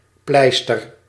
Ääntäminen
NL: IPA: /ˈplɛi.stər/